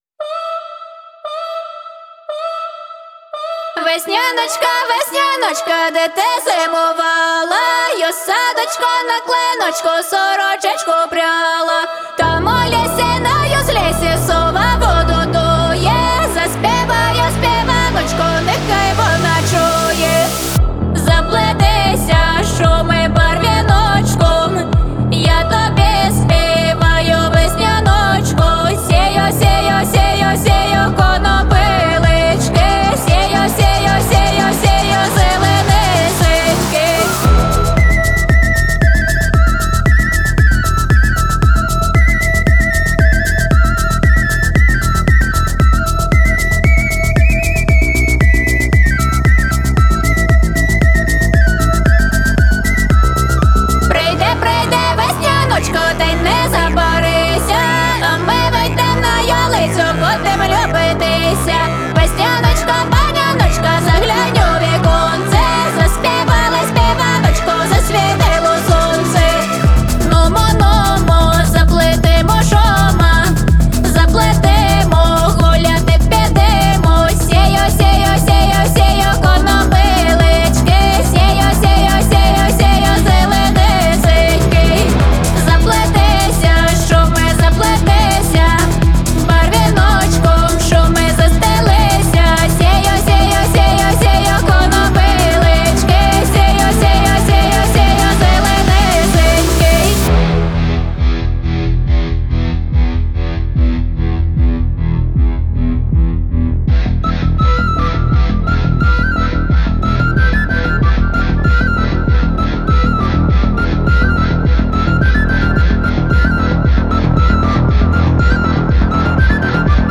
яркая и энергичная песня украинской группы